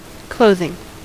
Ääntäminen
US : IPA : /ˈkloʊ.ðɪŋ/